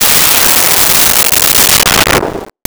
Missle 07
Missle 07.wav